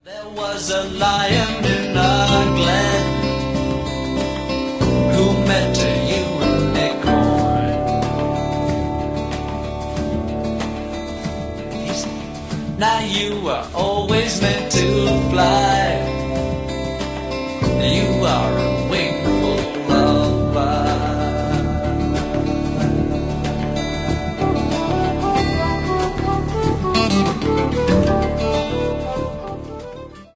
프로그레시브 록